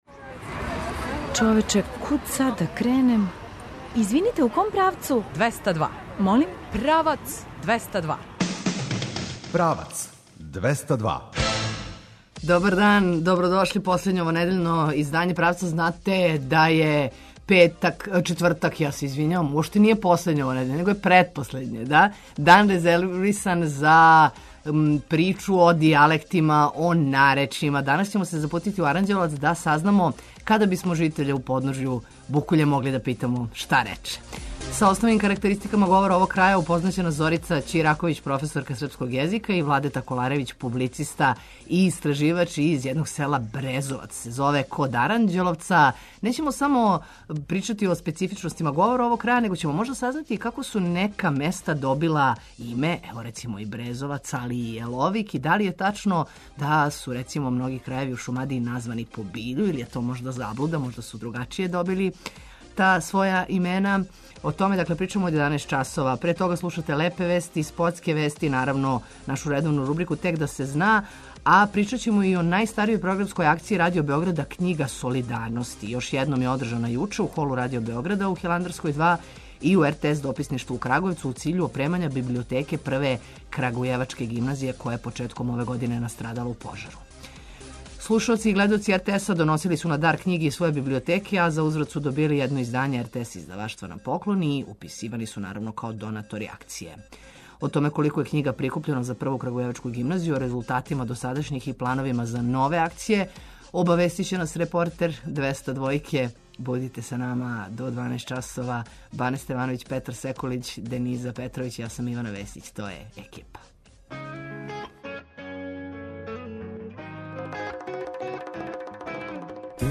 О томе колико је књига прикупљено за Прву крагујевачку гимназију, о резултатима досадашњих и плановима за нове акције обавестиће нас репортер 202-ке.